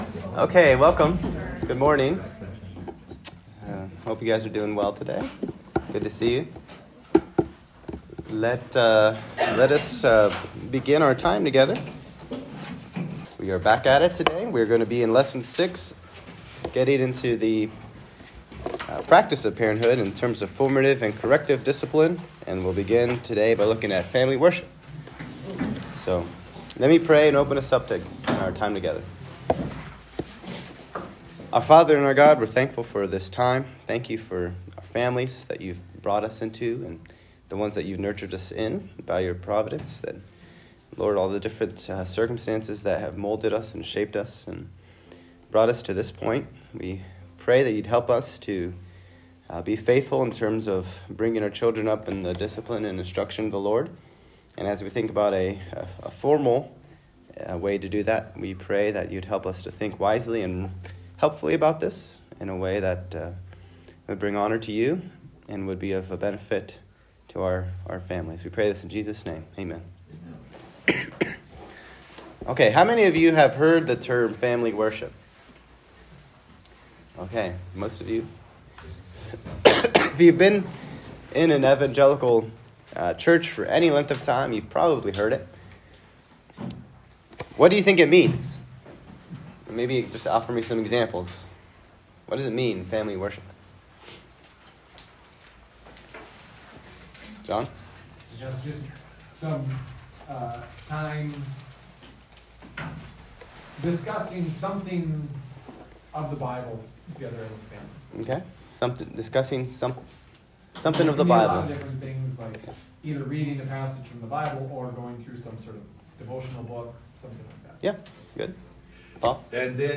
Lesson 6: Formative Discipline (Part 1) – Family Worship